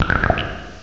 cry_not_roggenrola.aif